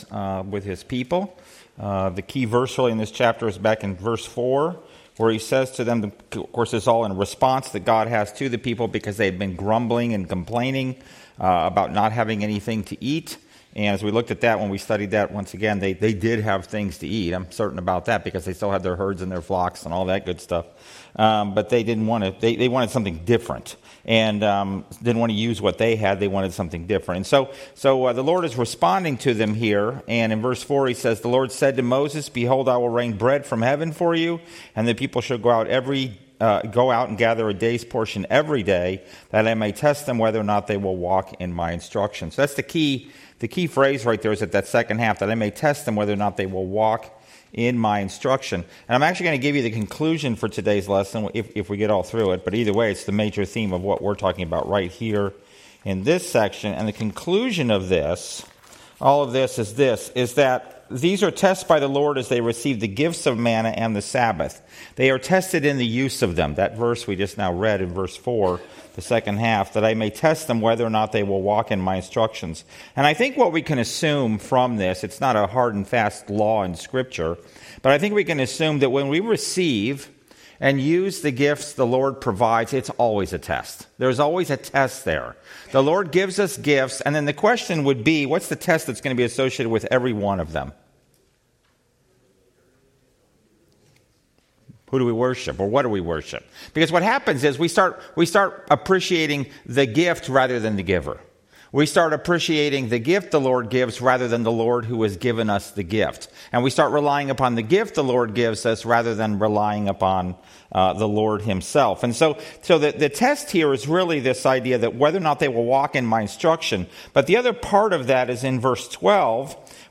Studies in Exodus Passage: Exodus 16 Service Type: Sunday School « Atonement